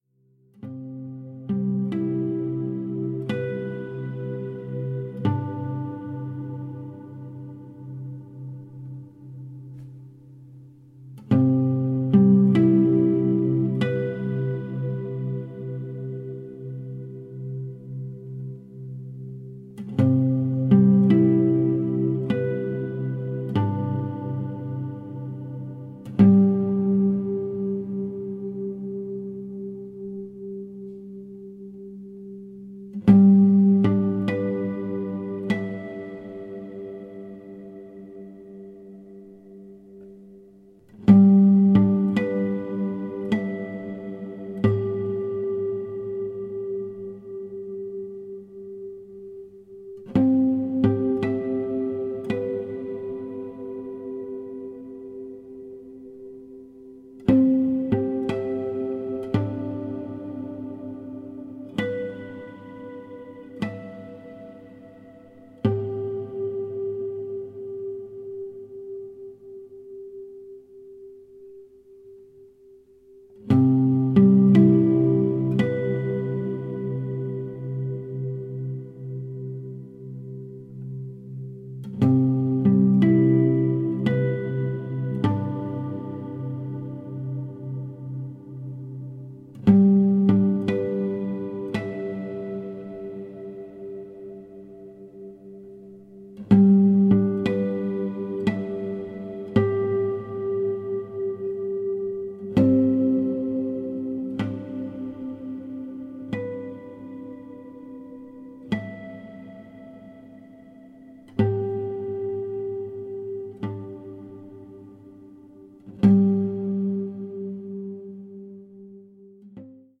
Méditation guitaristique très intériorisée.